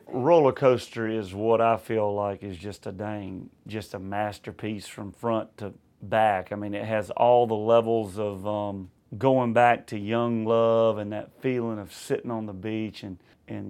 AUDIO: Luke Bryan says “Rollercoaster” is one of the best songs on the new album.